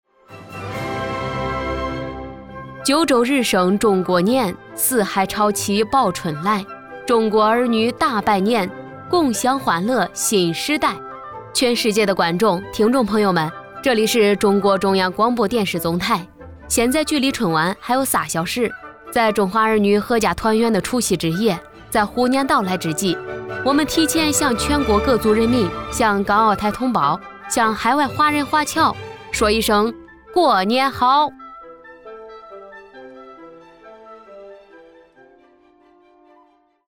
年轻大气-春晚主持